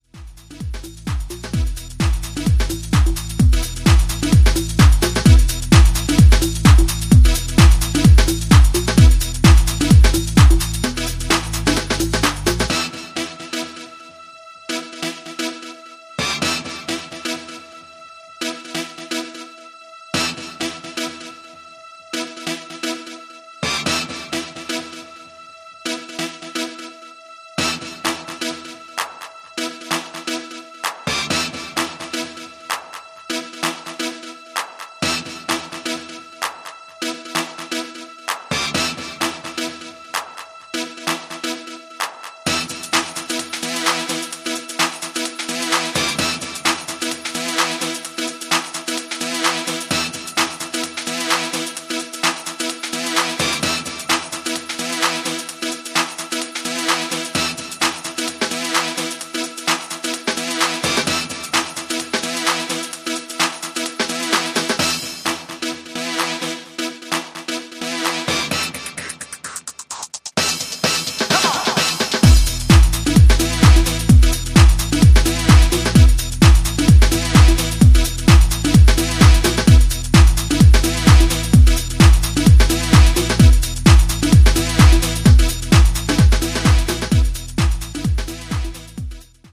ここでは、モダンでバウンシーなプログレッシヴ・ハウス路線の4曲を展開。
ド派手なスタブとベースラインでピークタイムを沸かせる